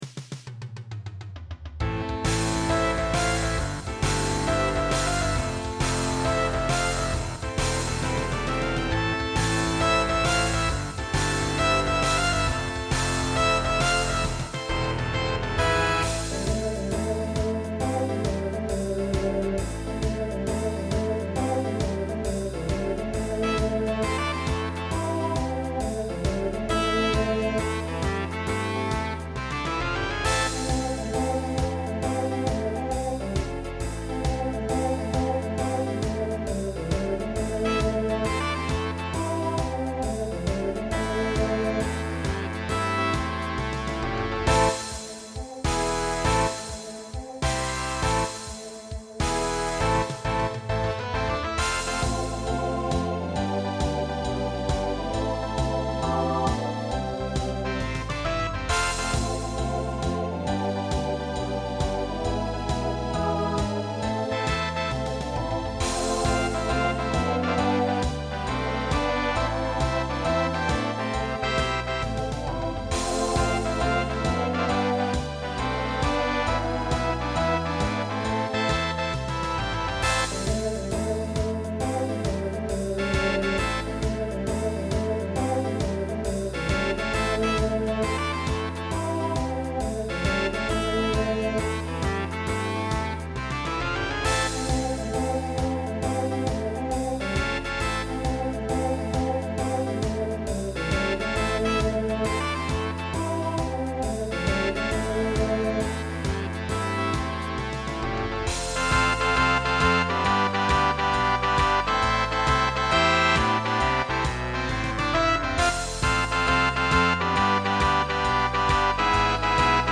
SATB|TTB/SSA